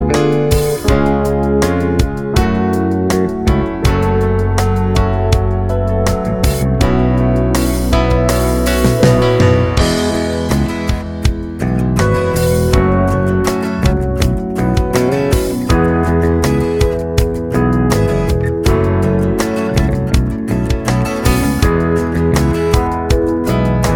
No Backing Vocals Duets 3:40 Buy £1.50